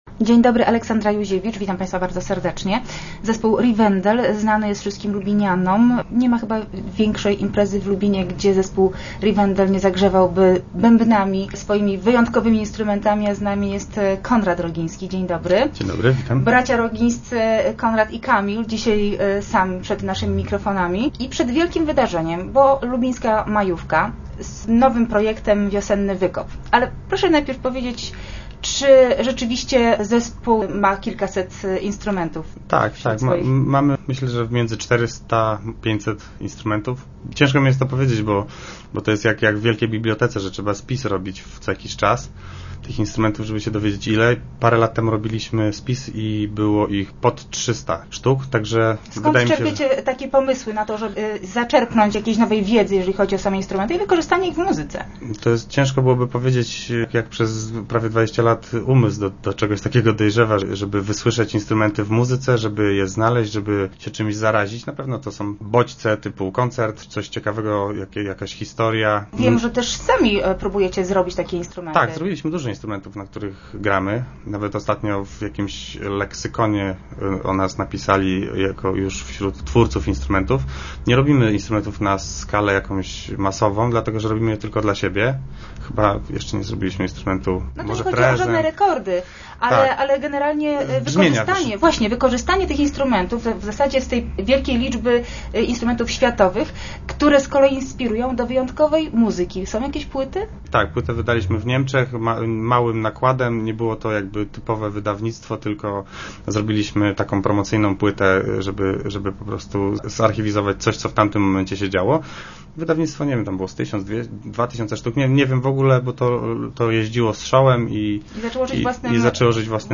Start arrow Rozmowy Elki arrow Zagrają na koparkach